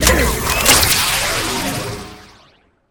laser1.ogg